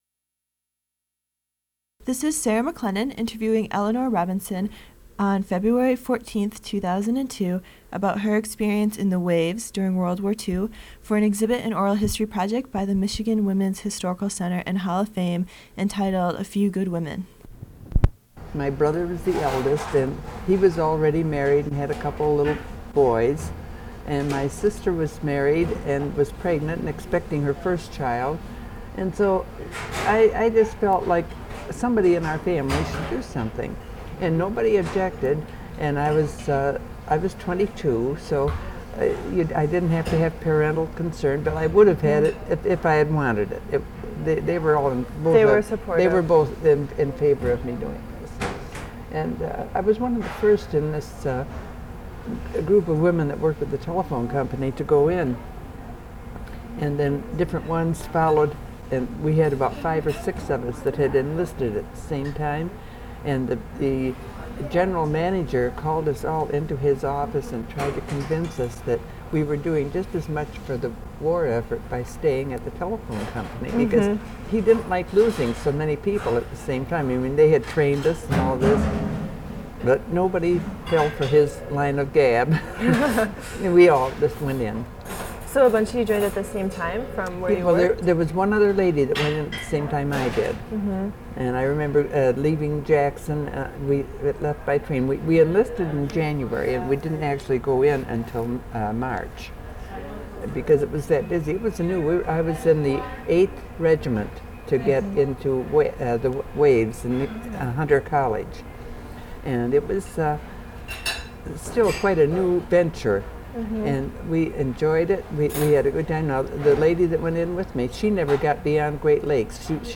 Oral histories (literary genre) Interviews